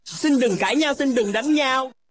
Xin đừng Cãi nhau Xin đừng Đánh nhau meme sound effect
Thể loại: Câu nói Viral Việt Nam
Description: Drama Open Relationship: Xin đừng cãi nhau, Xin đưng đánh nhau bản gốc meme sound effect hài hước và vui nhộn...